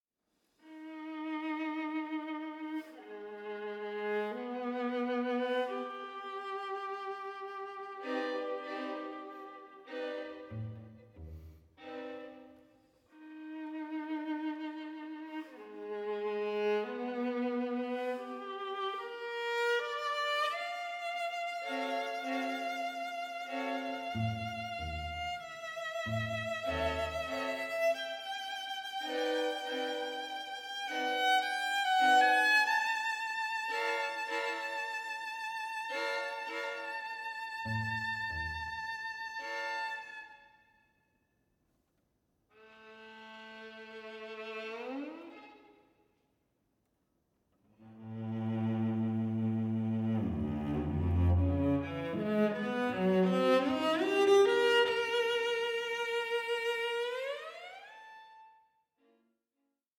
Adagio angoscioso 6:21